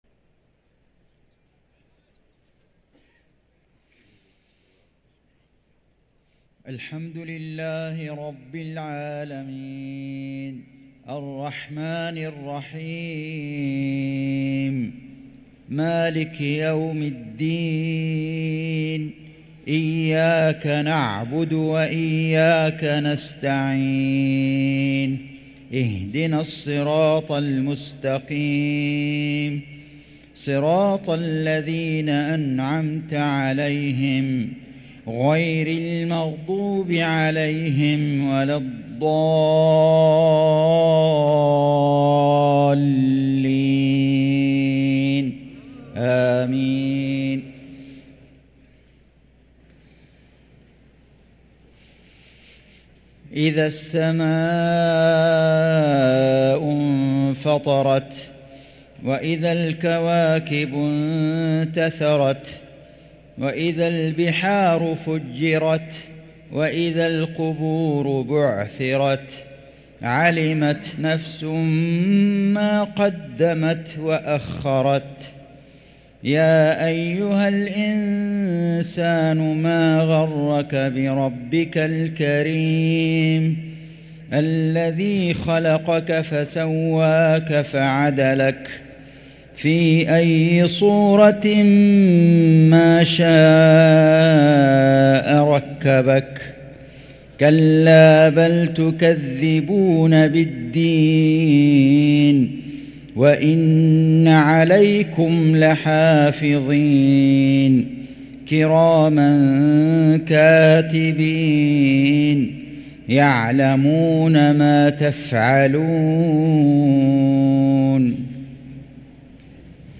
صلاة المغرب للقارئ فيصل غزاوي 5 رمضان 1444 هـ